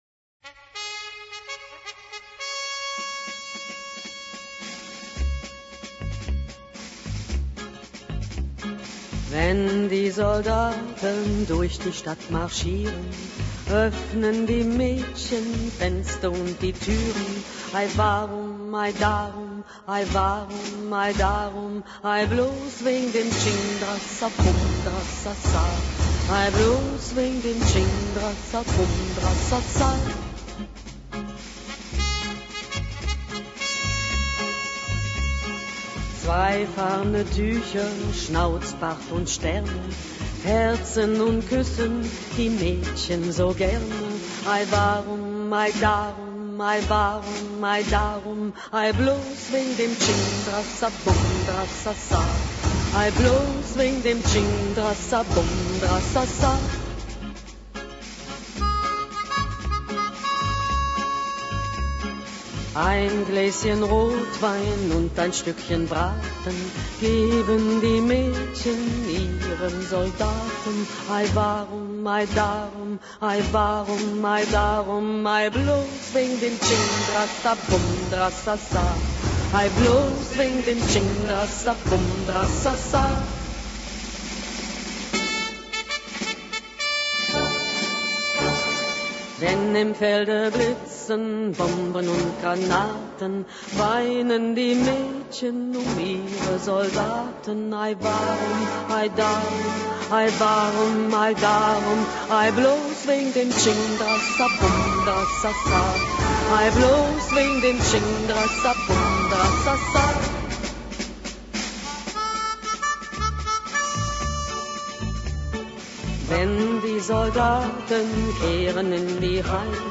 А вот «Wenn die Soldaten…» в исполнении Марлен Дитрих.